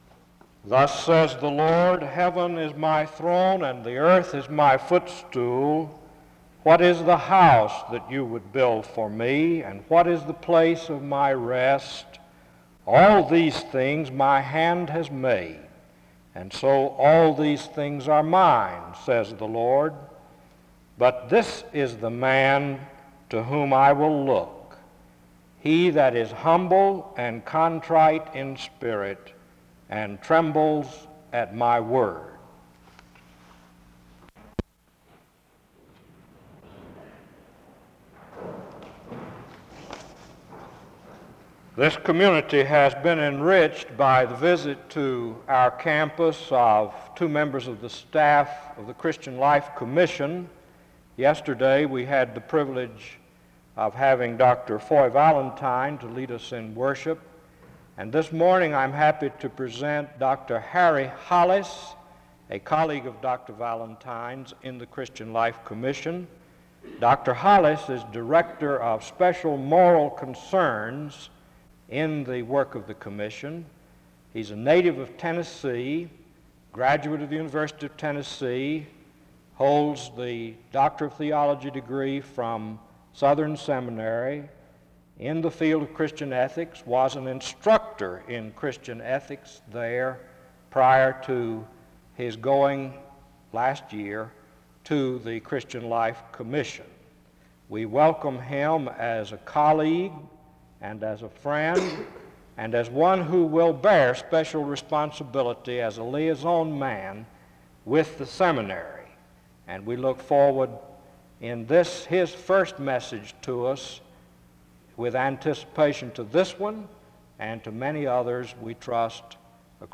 He closes in prayer (20:55-21:15).